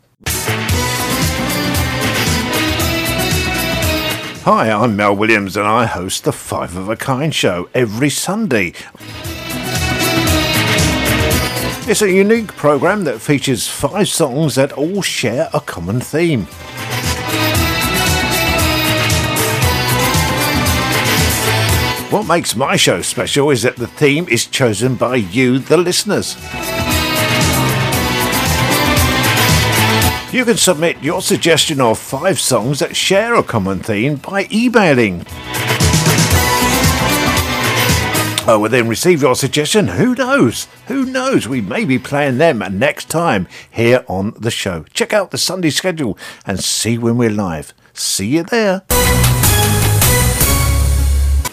5-of-a-kind-promo-new-2023.mp3